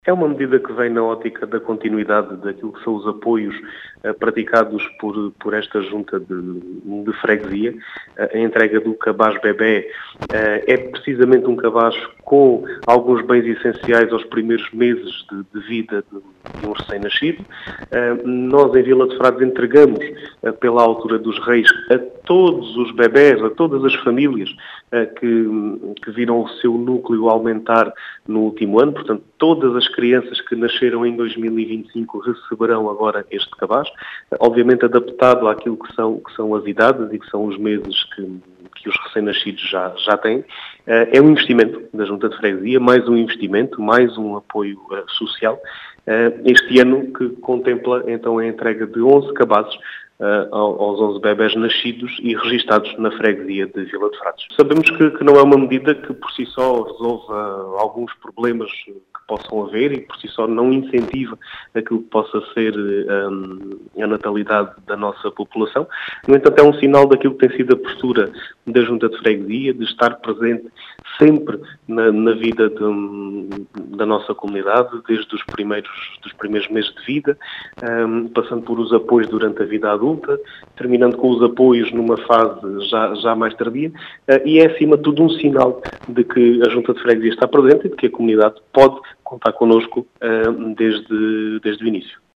As explicações são do presidente da junta de freguesia de Vila de Frades, Diogo Conqueiro, que realça a importância deste “apoio social”, com a entrega de 11 cabazes.